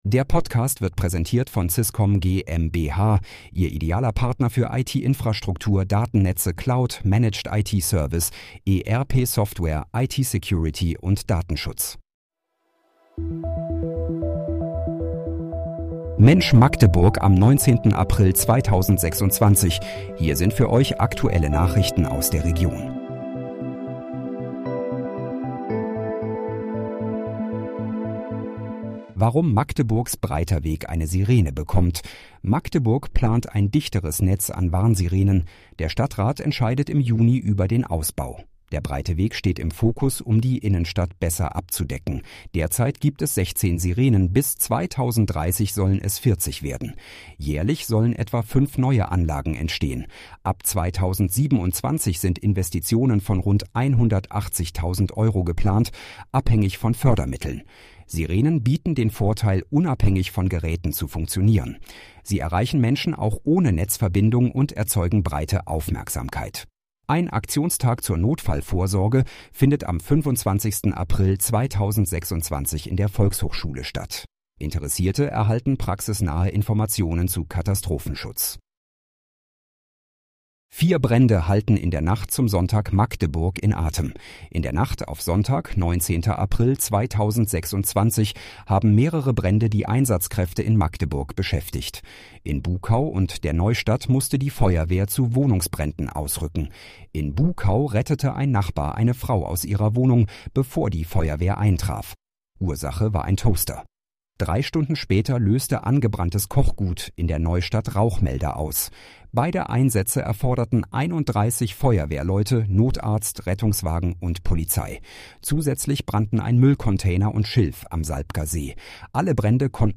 Mensch, Magdeburg: Aktuelle Nachrichten vom 19.04.2026, erstellt mit KI-Unterstützung